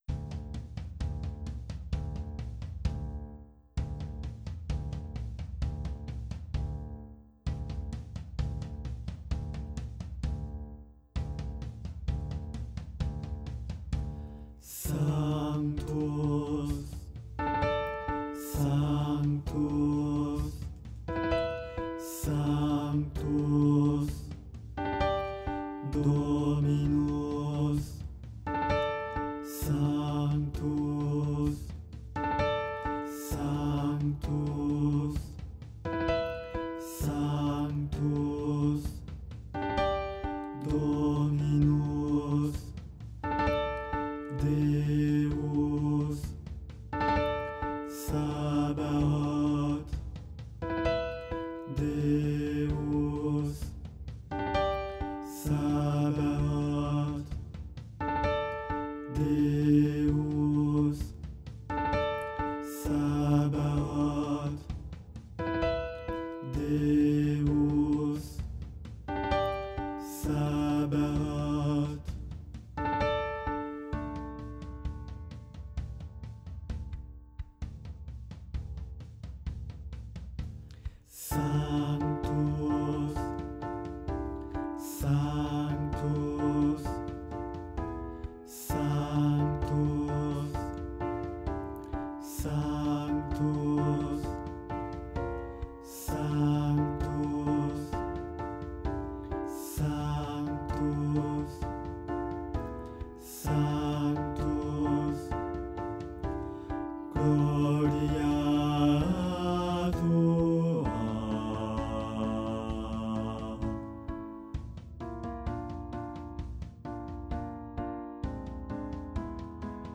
Voici les parties des différentes voix du sanctus.
• Voix de basse
8f2d2-sanctus-basse.wav